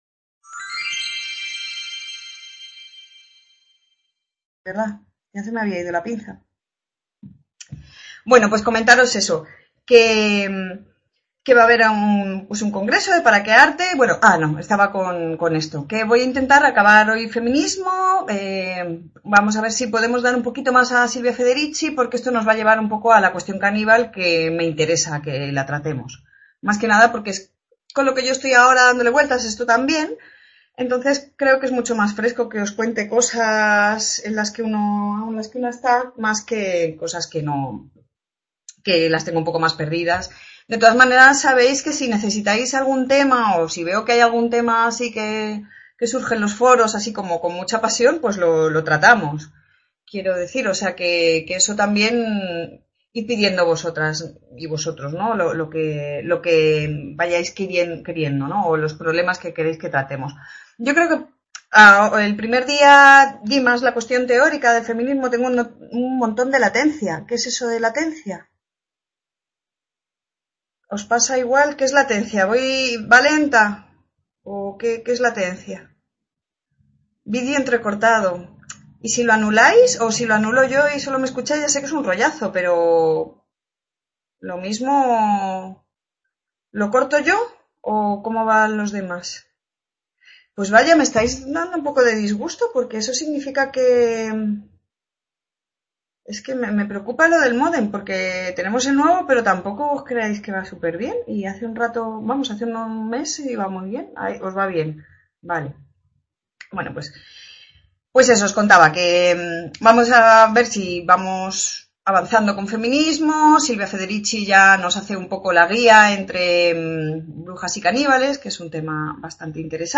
Clase 4 feminismos 2 | Repositorio Digital